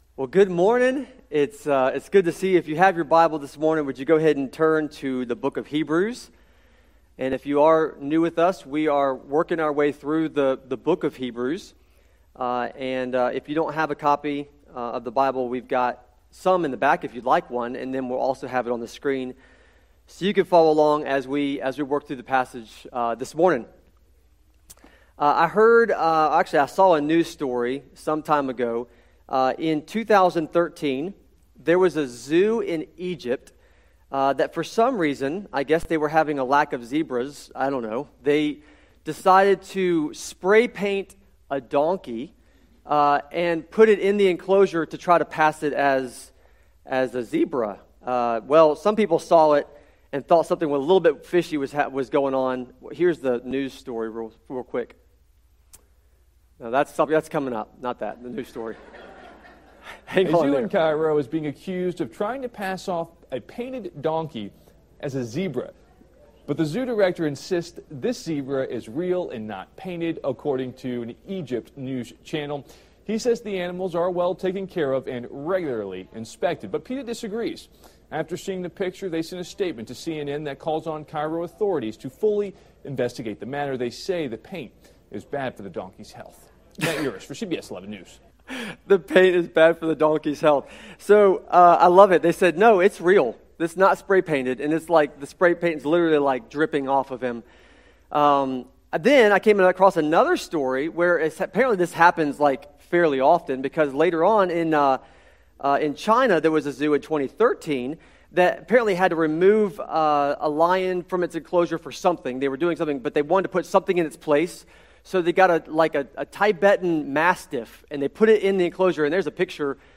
sermon-audio-trimmed-3.mp3